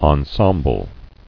[en·sem·ble]